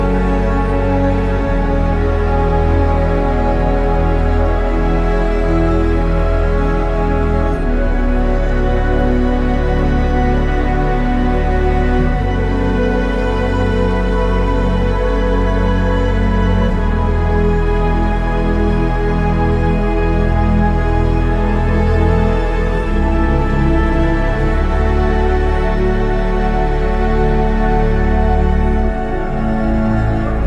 Dance pop, hymnes de stade et ballade amoureuse